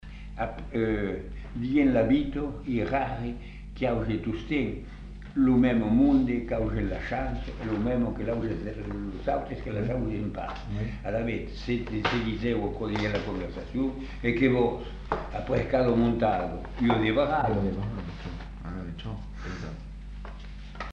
Lieu : Masseube
Genre : forme brève
Effectif : 1
Type de voix : voix d'homme
Production du son : récité
Classification : locution populaire